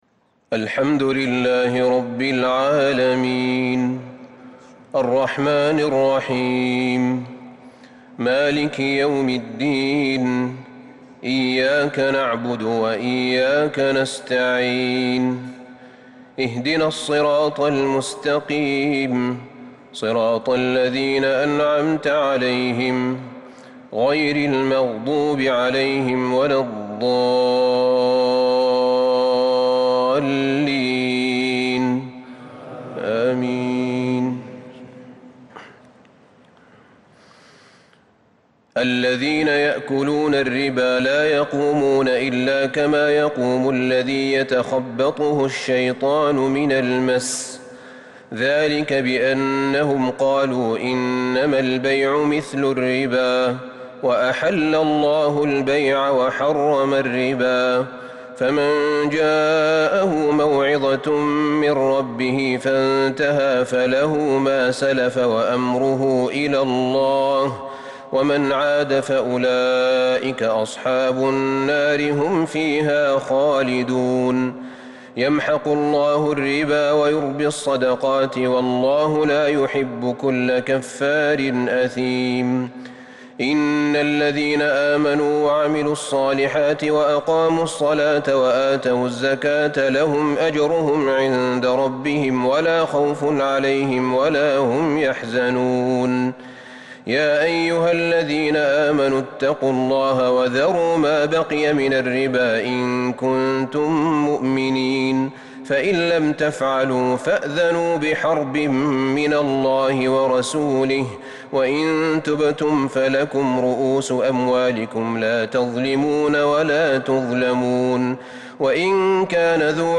تراويح ليلة 4 رمضان 1442هـ من سورتي البقرة {275-286} و آل عمران {1-41} Taraweeh 4st night Ramadan 1442H Surah Al-Baqara {275-286} Surah Aal-i-Imraan {1-41} > تراويح الحرم النبوي عام 1442 🕌 > التراويح - تلاوات الحرمين